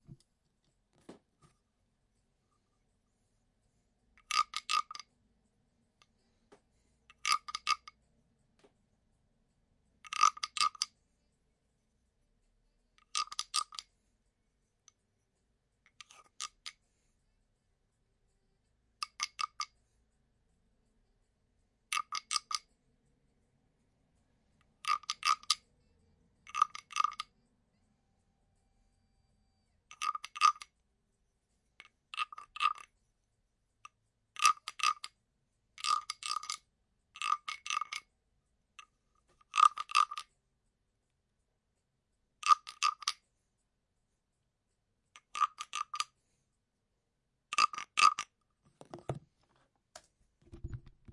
木制打击乐器青蛙2
描述：木打击乐青蛙
Tag: 青蛙 动物 非洲的 敲击